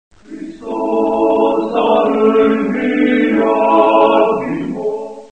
egg_explode.mp3